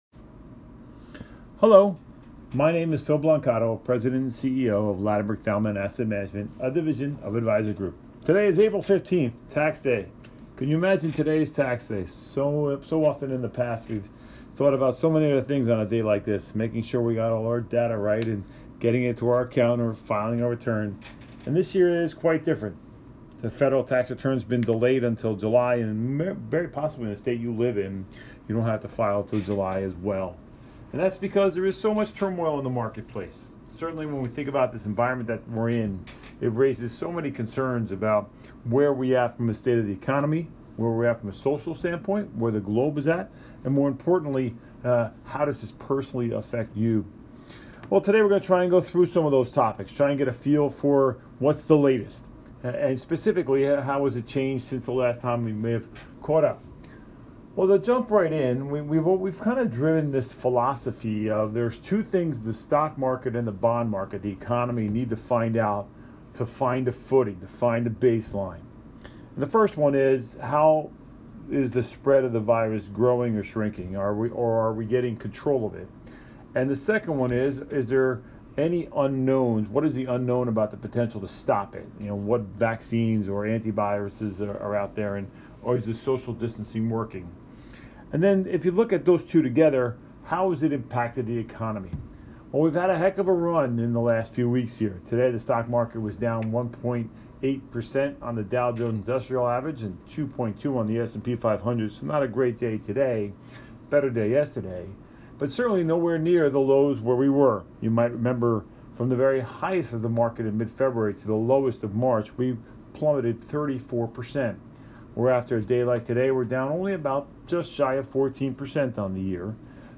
Market Impact Call